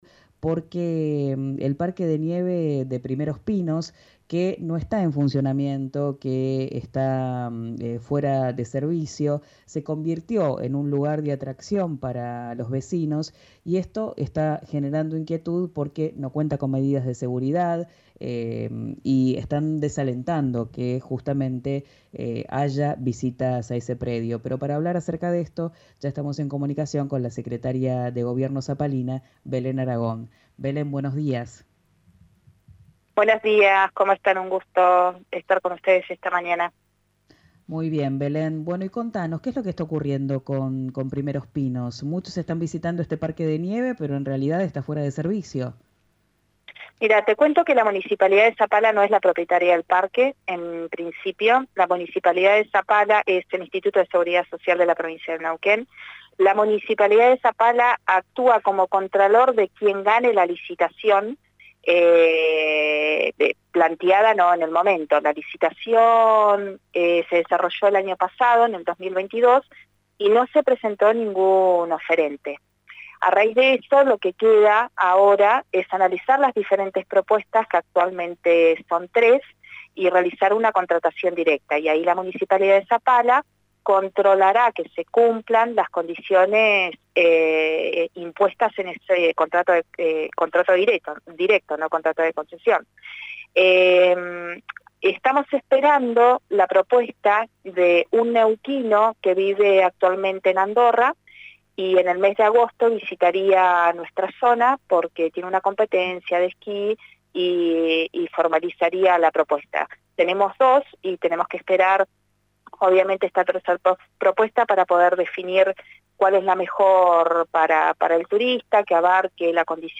Desde el municipio hablaron en RIO NEGRO RADIO acerca de un misterioso neuquino que vive en Andorra y está interesado en la concesión, mientras que recomendaron no asistir por varios motivos.